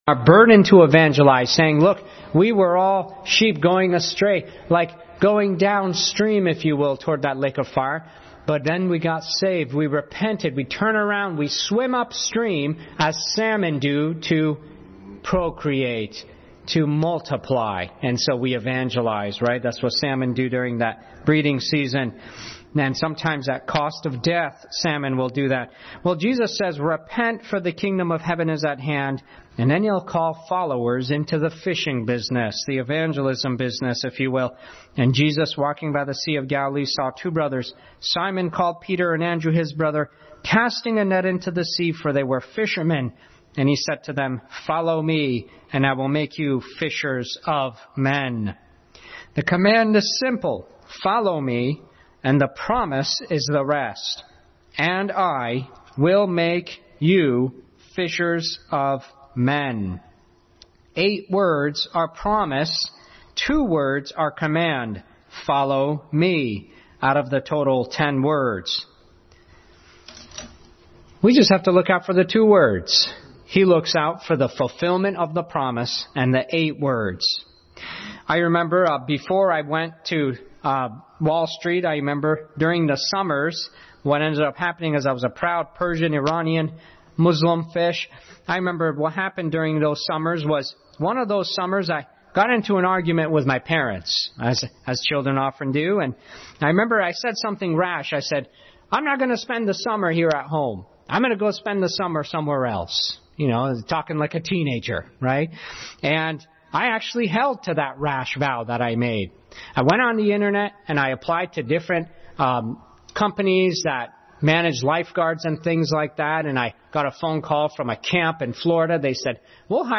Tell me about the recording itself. Passage: Matthew 4:13-20, John 12:26 Service Type: Sunday School